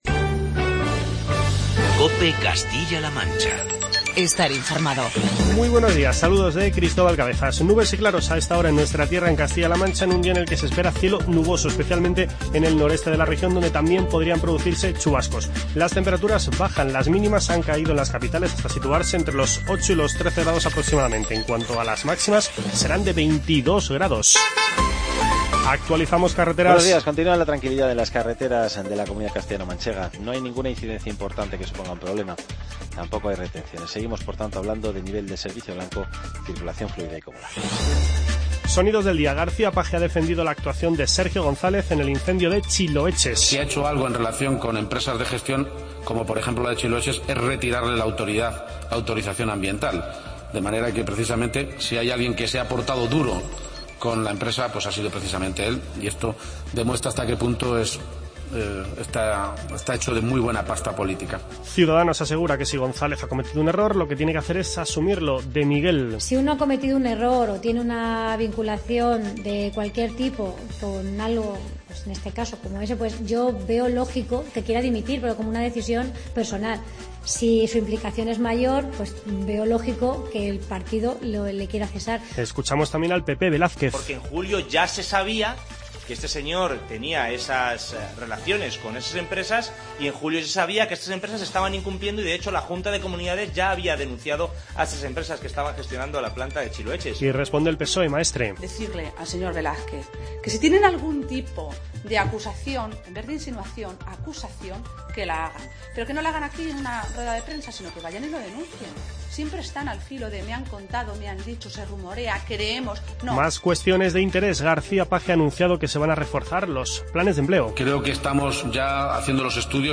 Escuchamos en nuestras "Voces de los Protagonistas" las palabras de Emiliano García-Page, Orlena de Miguel, Carlos Velázquez y Cristina Maestre.